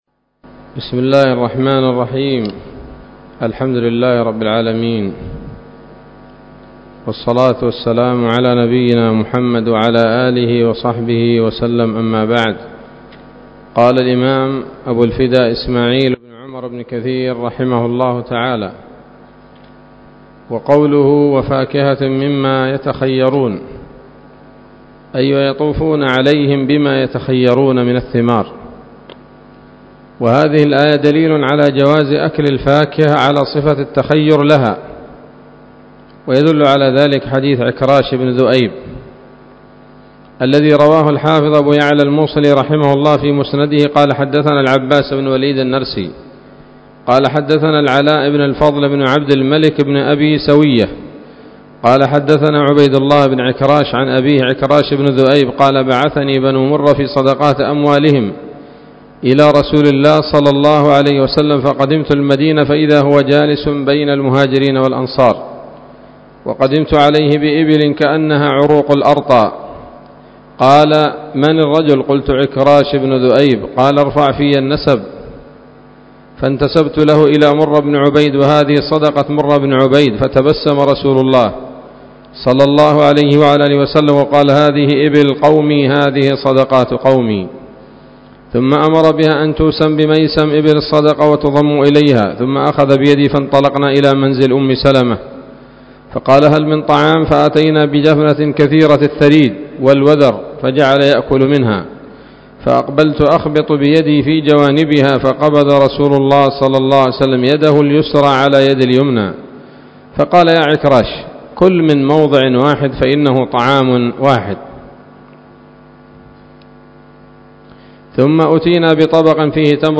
الدرس الخامس من سورة الواقعة من تفسير ابن كثير رحمه الله تعالى